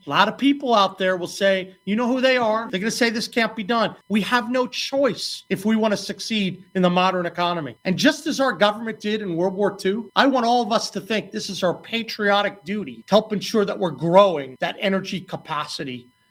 The centerpiece is the governor’s aggressive “50 by 50” generation plan to increase energy capacity in the state to 50 gigawatts by 2050 from its current 15 gigawatts. Morrisey says he knows there are those who say it is not possible to achieve…